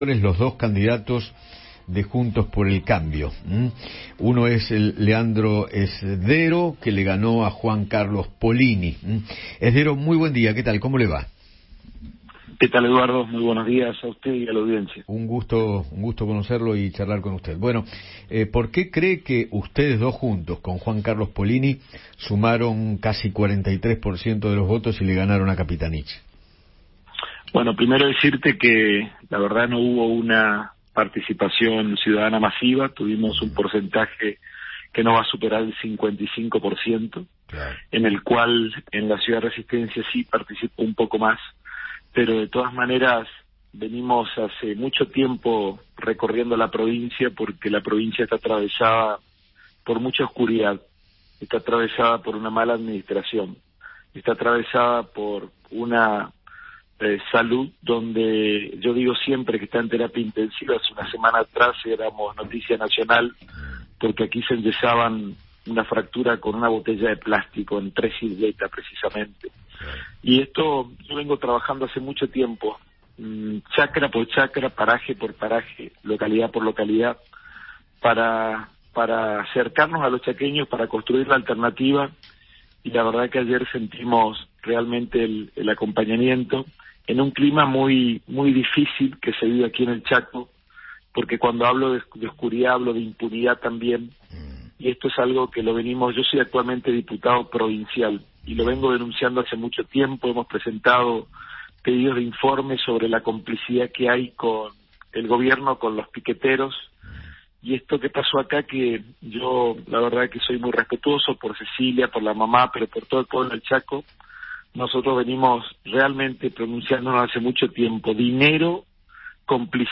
Leandro Zdero, candidato a gobernador de Juntos por el Cambio en Chaco, habló con Eduardo Feinmann tras ganarle la interna a Juan Carlos Polini en las PASO de la provincia y se mostró esperanzador de cara al futuro